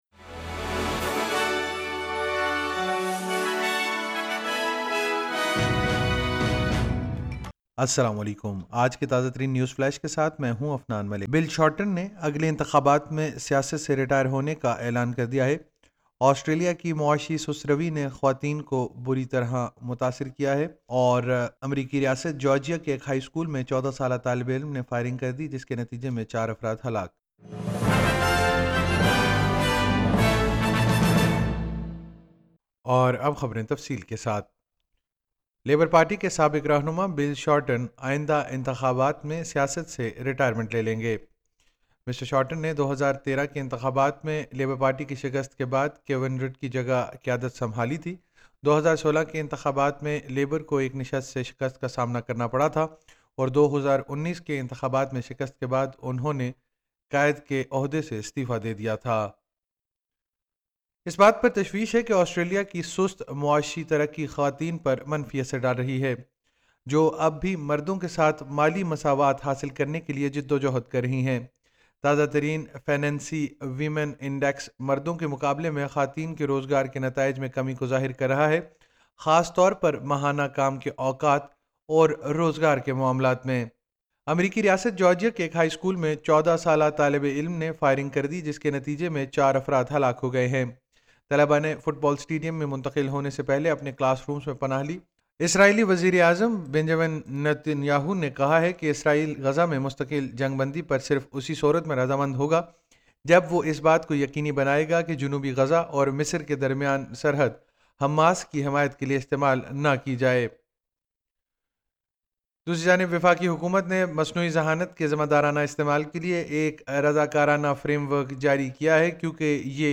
نیوز فلیش بدھ 05 ستمبر 2024: بل شارٹن اگلے انتخابات میں سیاست سے ریٹائر ہو جائیں گے